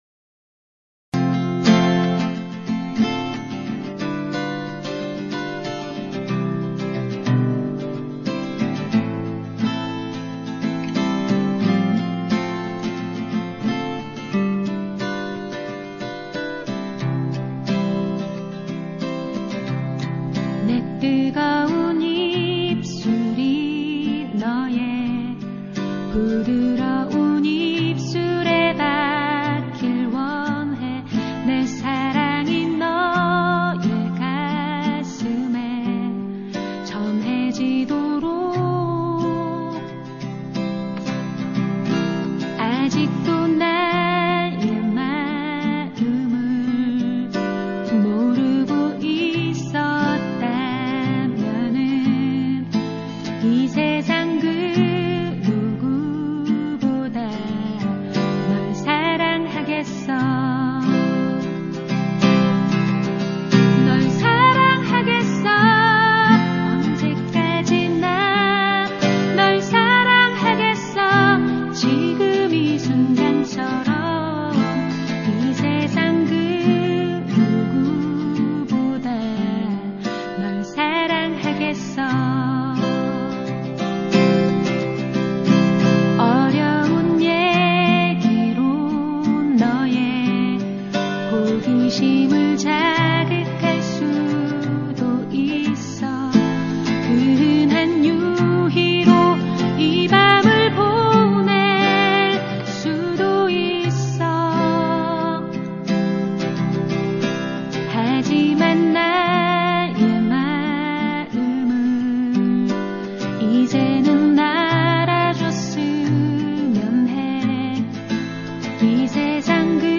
10년 가까이 언더그라운드에서 갈고닦은 여성 포크뮤지션